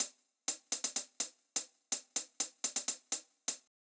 IBI Beat - Hats.wav